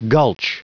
Prononciation du mot gulch en anglais (fichier audio)
Prononciation du mot : gulch